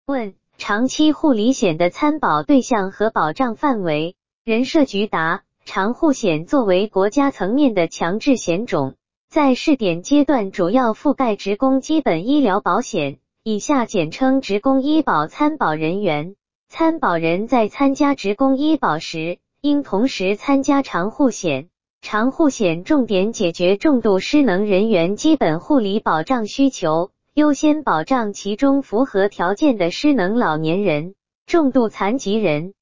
语音播报
语音合成中，请耐心等待...